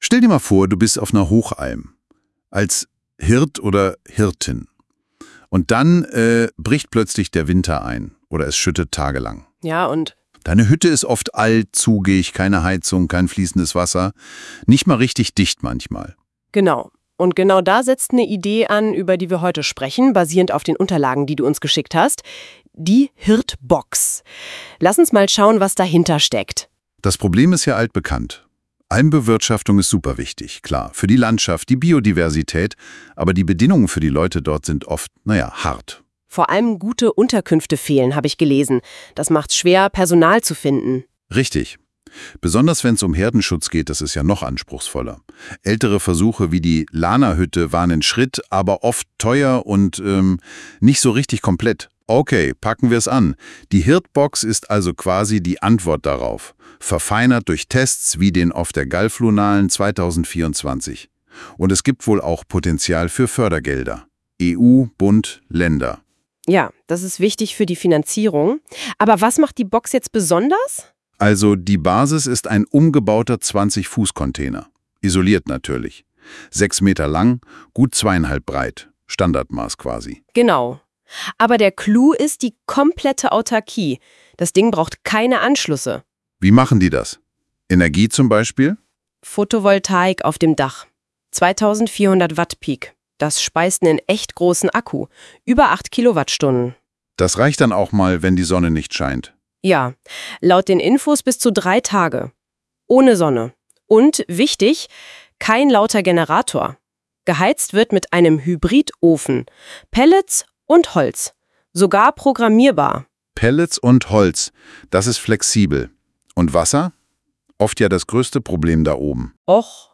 Rap Song über das Hirtenwesen aus der Sicht einer Hirtin Die Lösung: Die HirtenBox – mobil, autark, durchdacht Die HirtenBox wurde von Grund auf neu gedacht.